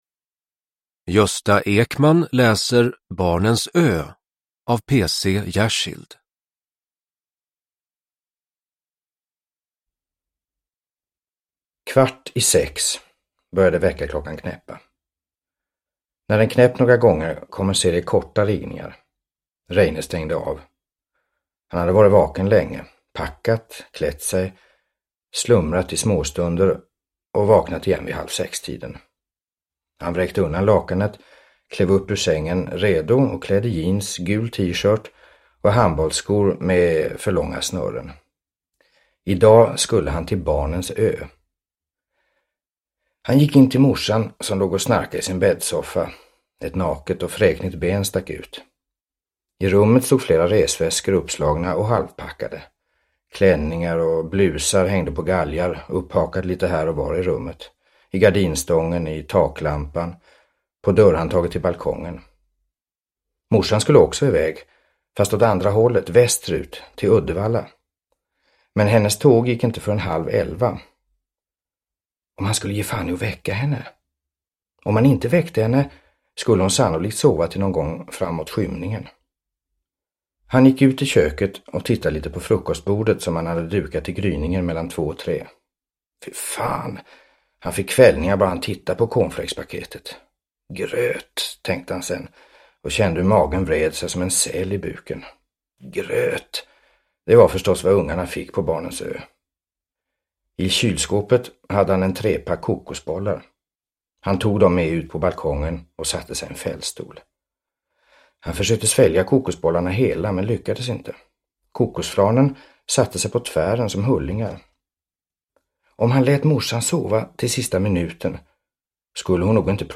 Barnens ö – Ljudbok – Laddas ner
Uppläsare: Gösta Ekman